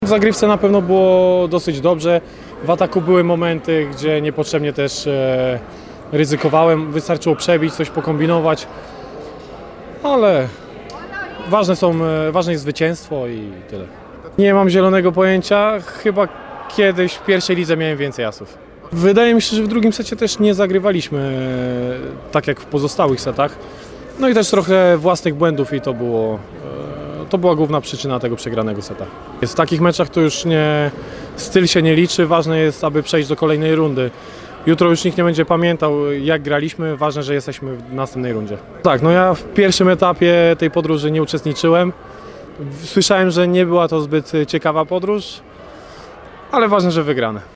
Wypowiedzi po rewanżowym meczu z Neftochimikiem Burgas (AUDIO)